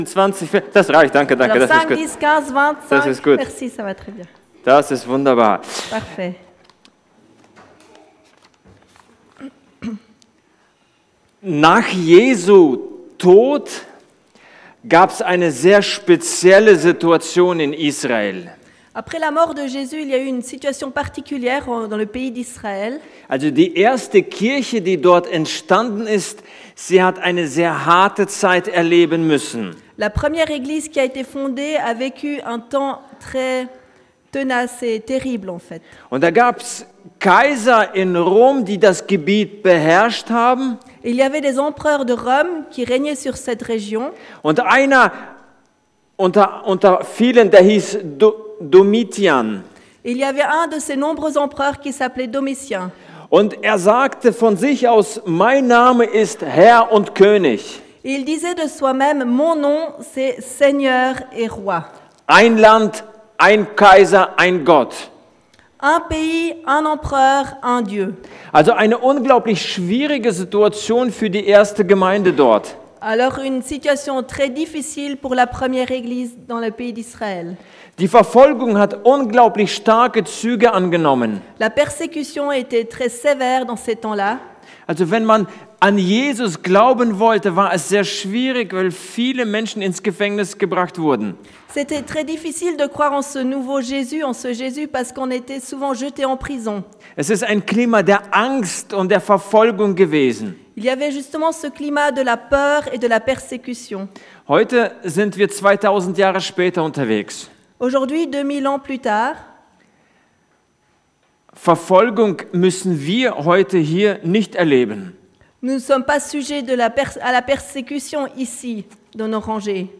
Weihnachts-Gottesdienst › Stadtmission Echandens